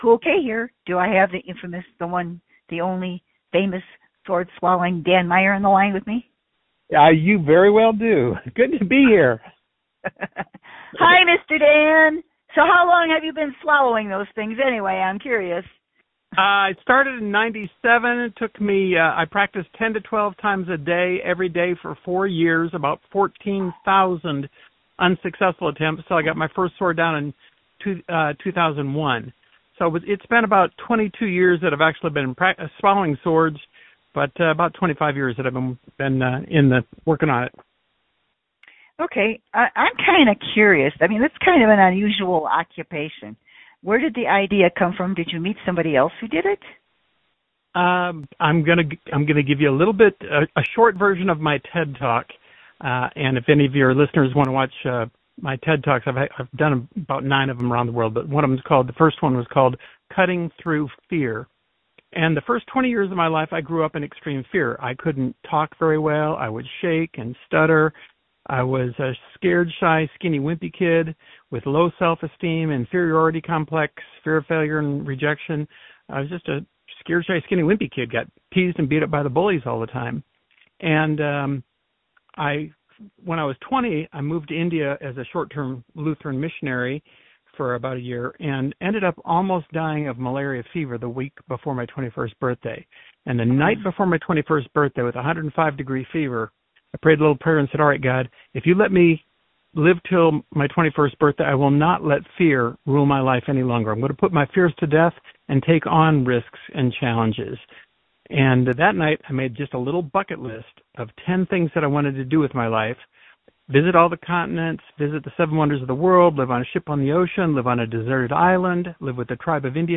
Podcast Interview with Entertainer